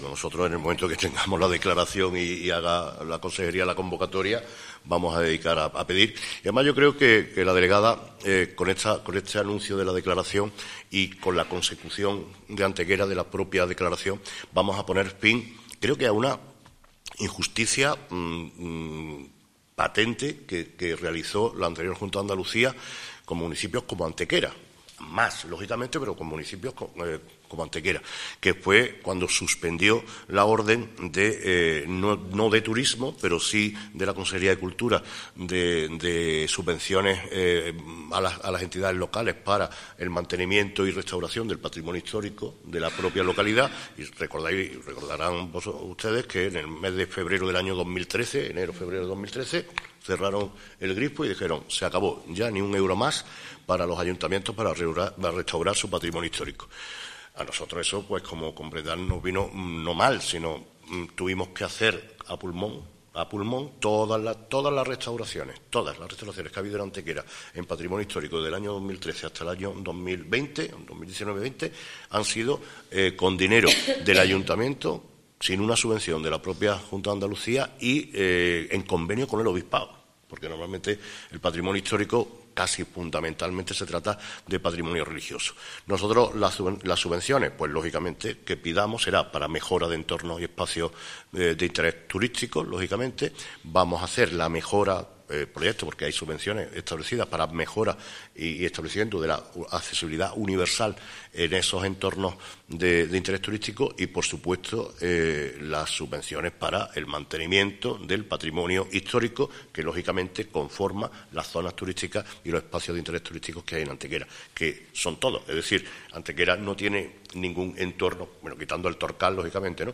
Así lo han anunciado en una rueda de prensa celebrada en el Salón de los Reyes y en la que la Delegada explicaba que ya ha quedado culminado todo el expediente al respecto, quedando solo su aprobación definitiva en el mes de febrero a través del Consejo de Gobierno de la Junta de Andalucía.
Cortes de voz